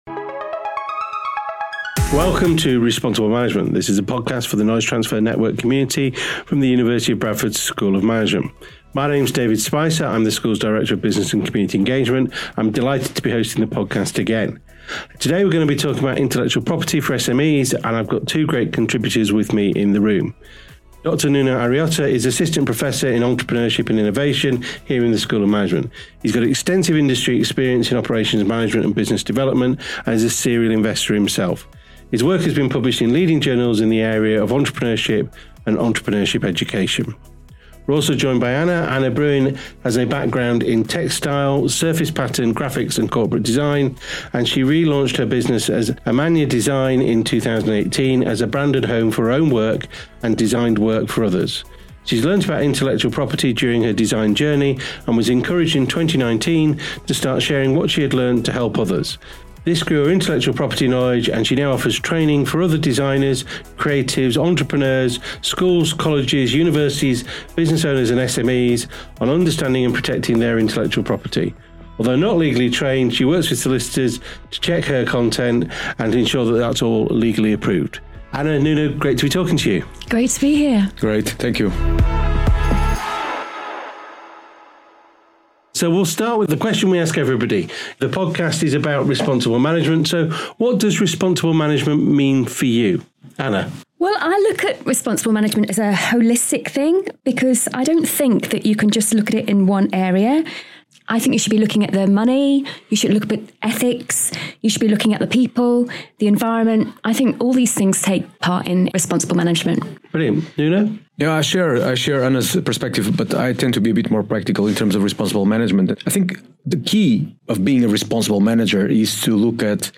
The conversation explores why businesses should be concerned with Intellectual Property and how they can appropriate manage and promote responsible management through IP in their firms. Both use their expertise from personal and professional experience to explore how brands across the globe can protect their assets.